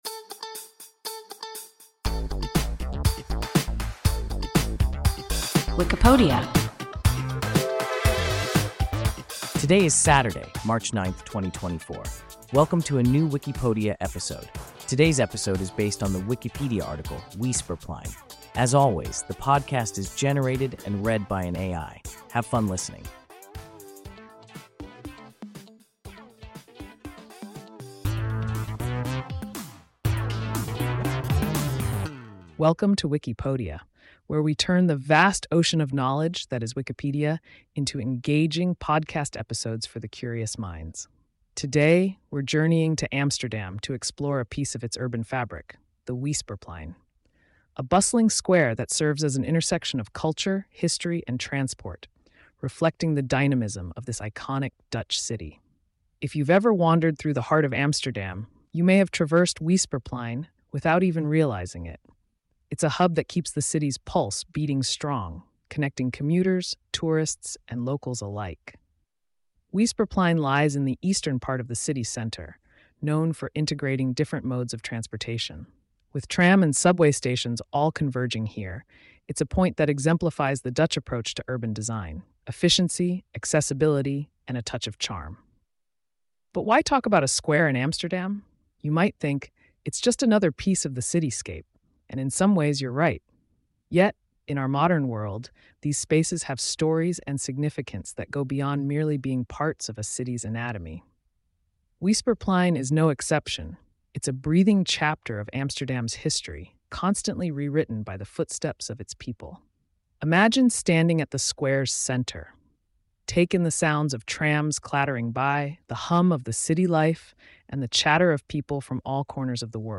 Weesperplein – WIKIPODIA – ein KI Podcast